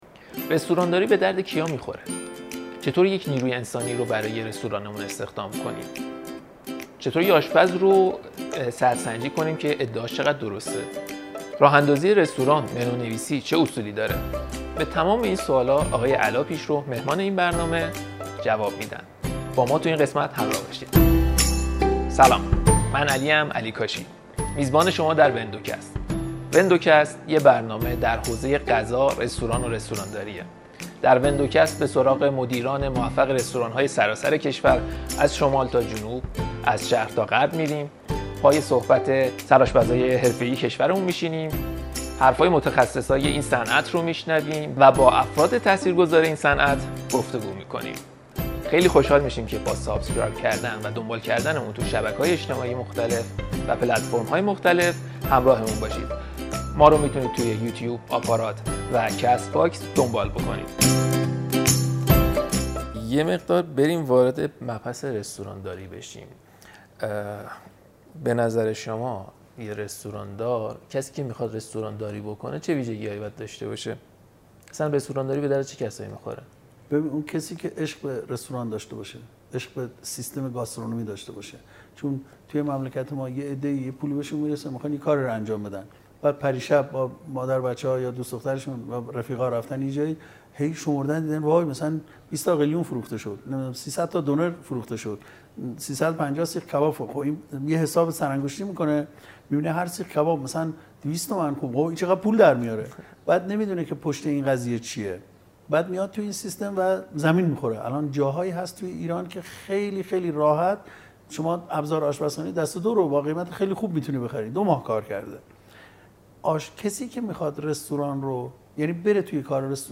در این گفت‌وگو مورد راه‌اندازی و تیم‌های راه‌انداز رستوران گفت‌وگوهای چالشی و جذابی انجام شد و نکته‌ها و تکنیک‌هایی برای تعامل و استفاده بهتر از این تیم‌ها بیان شد.